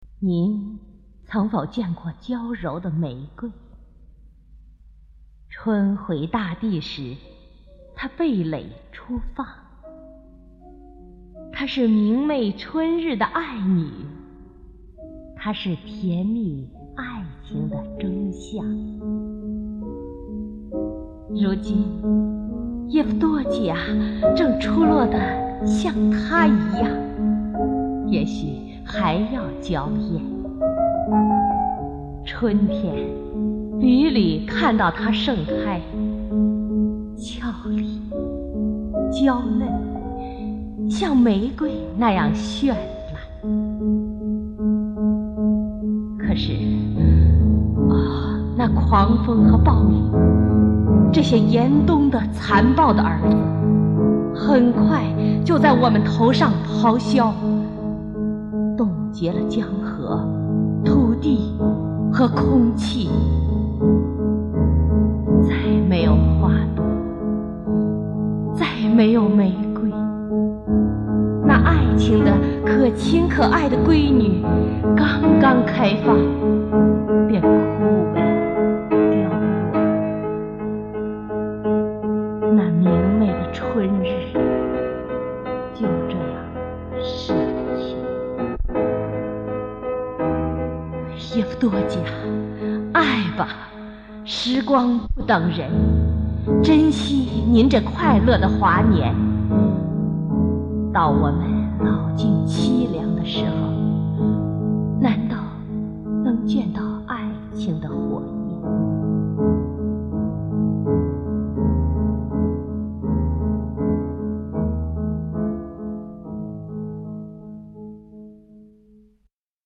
普希金诗配乐朗诵
（由录音带转录）